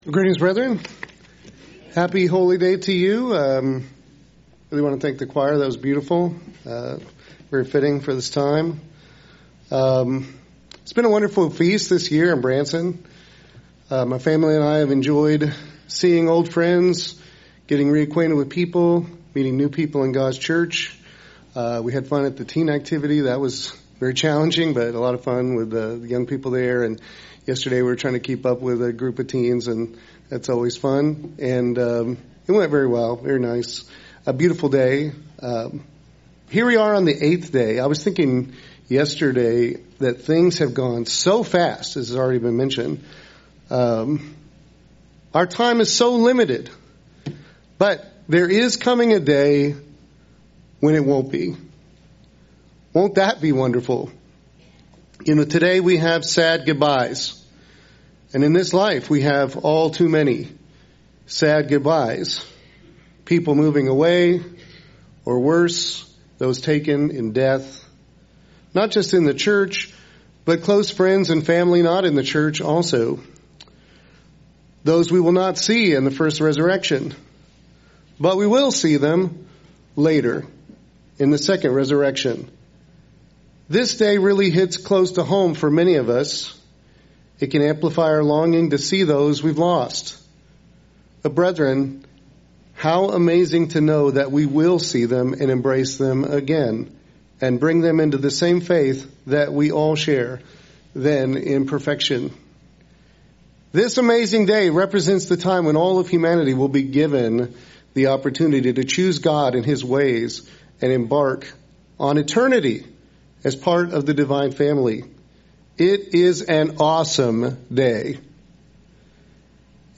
This sermon will show that the Bible teaches the universal offer of salvation to all humanity in the second or general resurrection period.